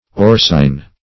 Search Result for " orcein" : The Collaborative International Dictionary of English v.0.48: Orcein \Or"ce*in\, n. (Chem.) A reddish brown amorphous dyestuff, C7H7NO3 , obtained from orcin, and forming the essential coloring matter of cudbear and archil.